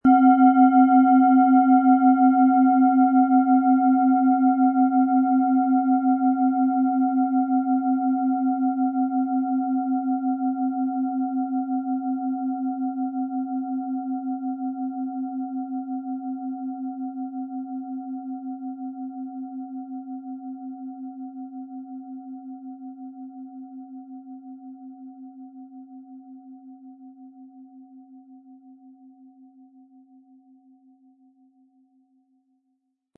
Planetenschale® DNA-Reparatur & Glücksplanet mit DNA-Ton & Jupiter, Ø 16,8 cm, 600-700 Gramm inkl. Klöppel
• Tiefster Ton: Jupiter
PlanetentöneDNA & Jupiter
HerstellungIn Handarbeit getrieben
MaterialBronze